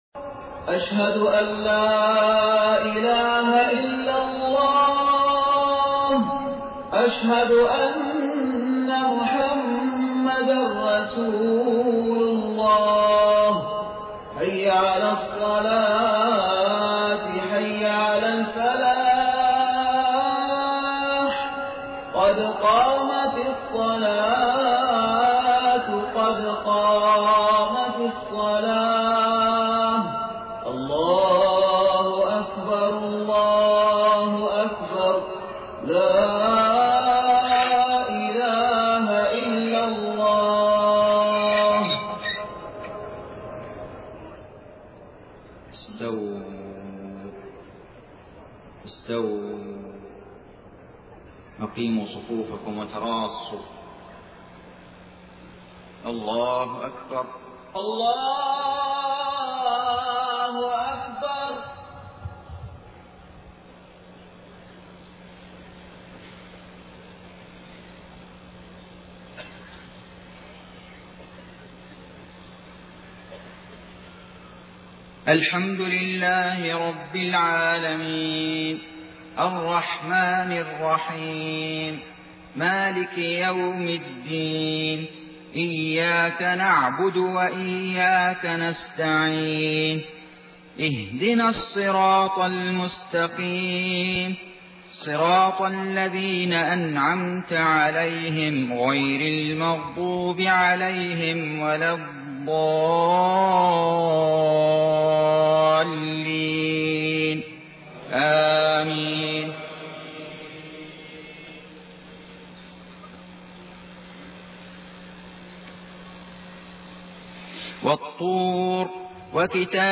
صلاة الفجر 27 صفر 1431هـ سورة الطور كاملة > 1431 🕋 > الفروض - تلاوات الحرمين